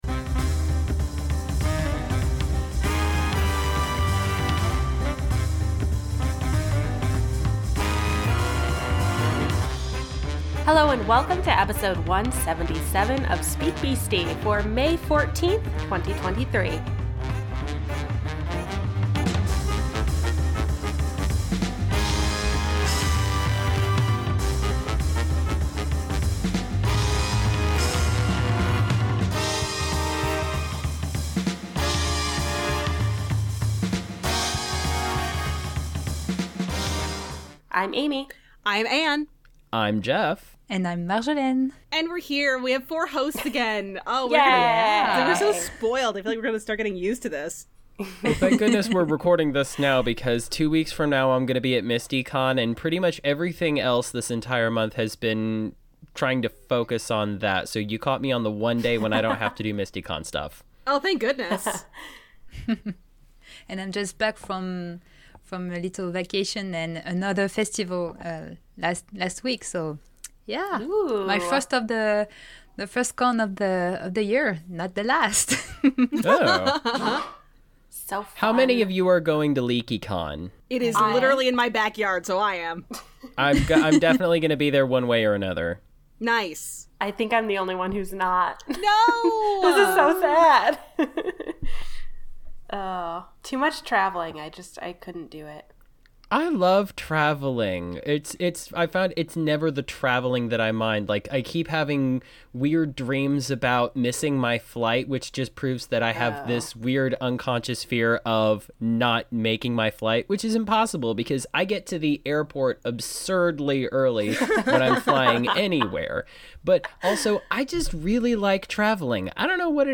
We’re here and we have four hosts AGAIN!